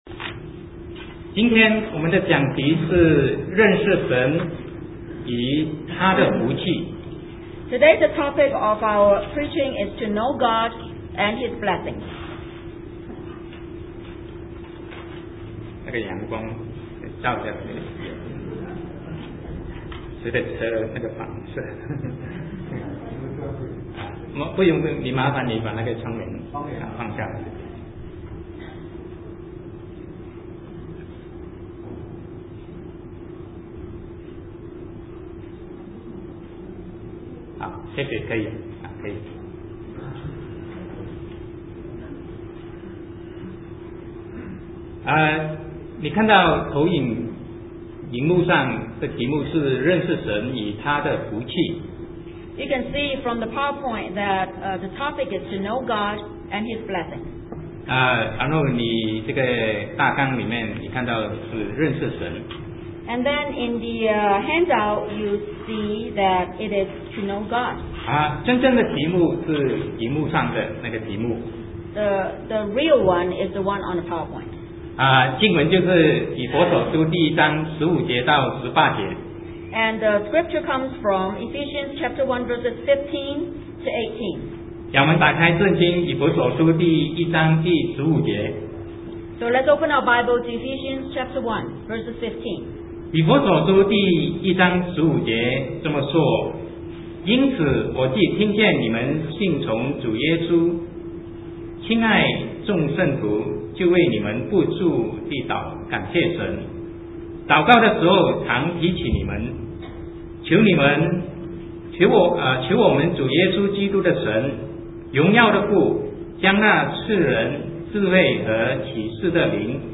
Sermon 2008-04-13 To Know God and His Blessing